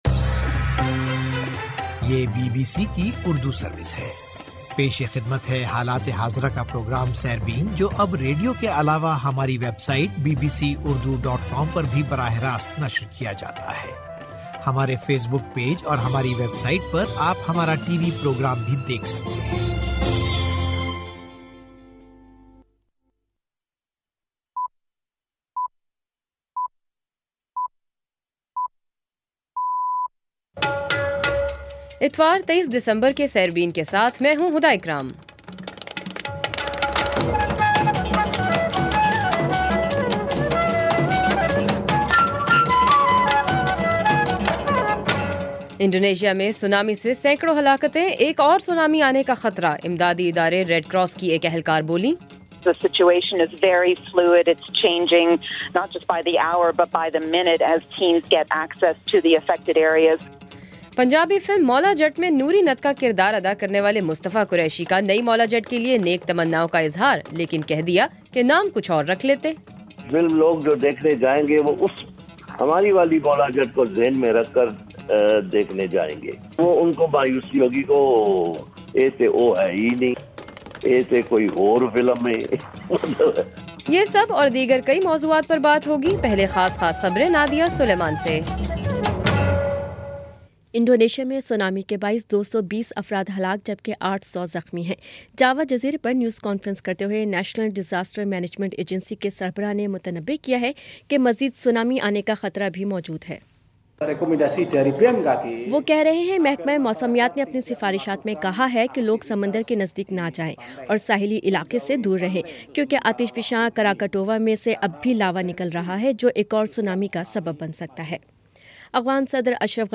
اتوار 23 دسمبر کا سیربین ریڈیو پروگرام